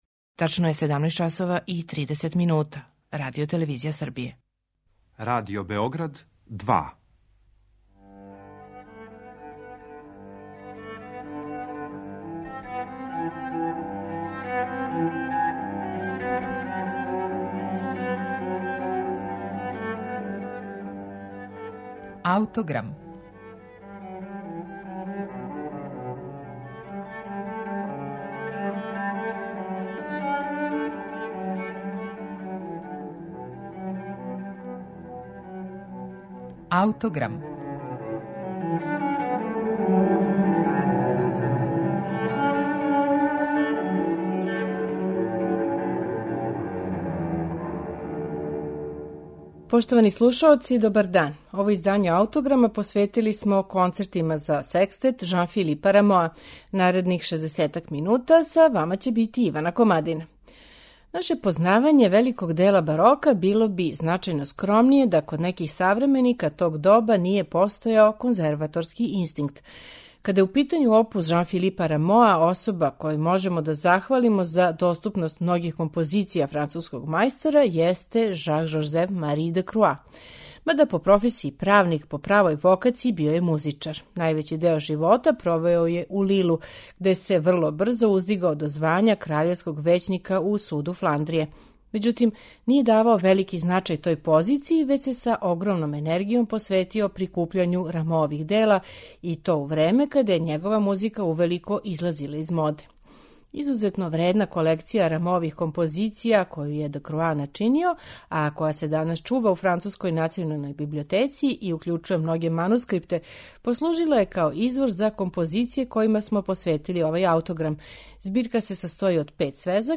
Четири концерта за секстет